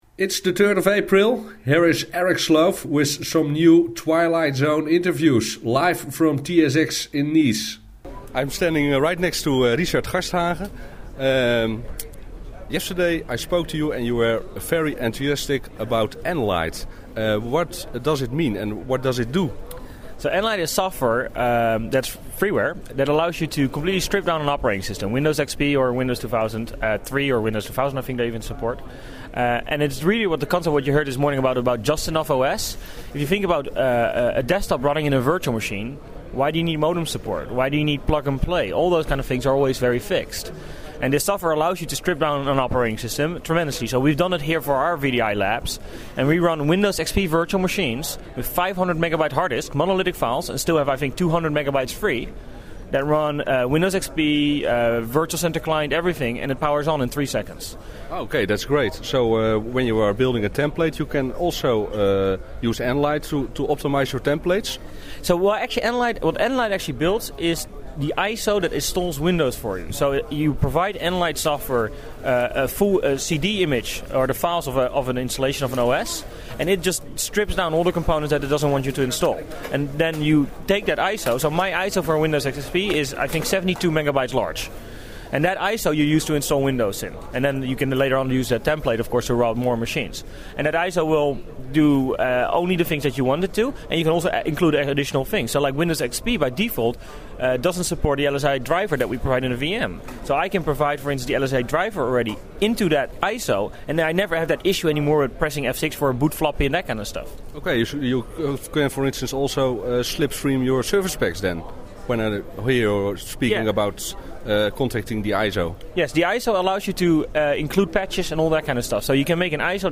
TSX Interview